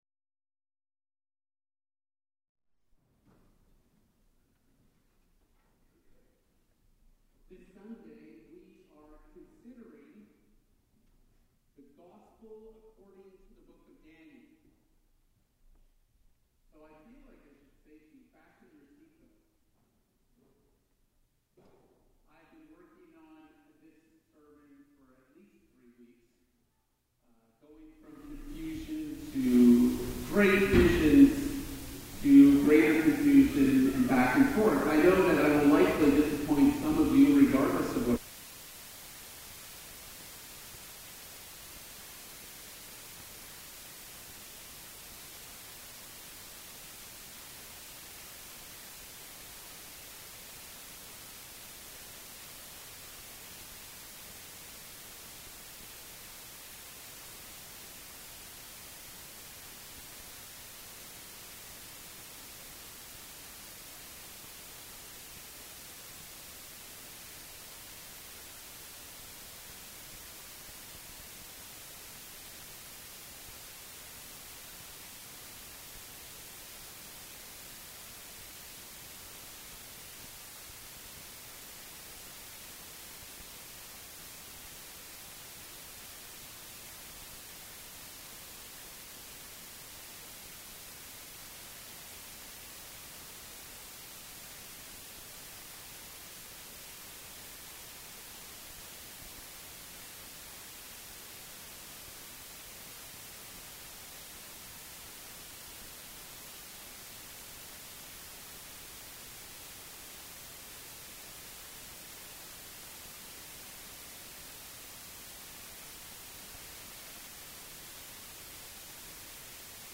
Sermons | First Baptist Church